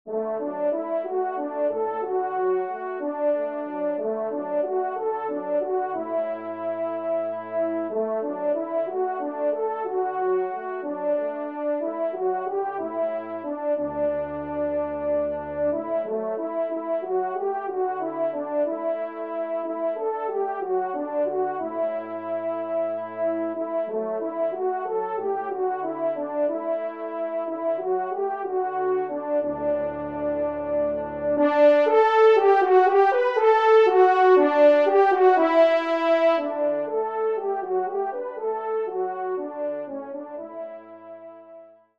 Pupitre 1° Cor (en exergue)